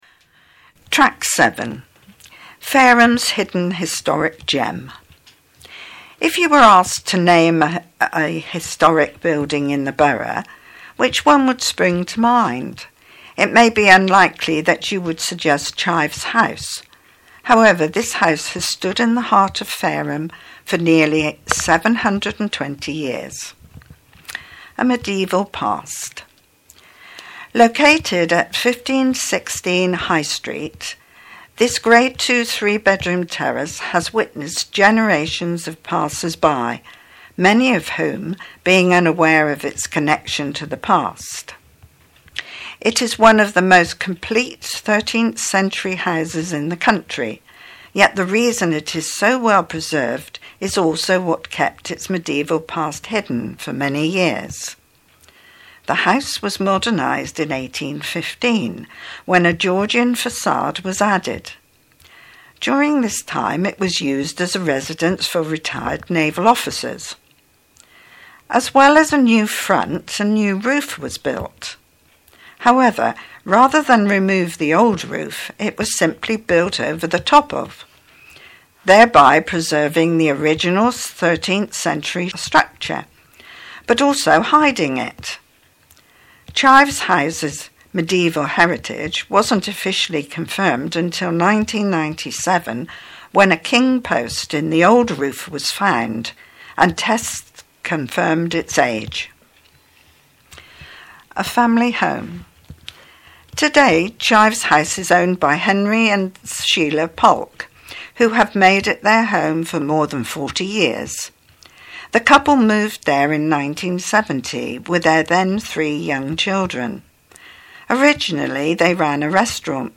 An introduction to Fareham Today and what's inside. Also introduces the presenters from Fareport Talking News.
A message from the Leader of the Council, Councillor Sean Woodward.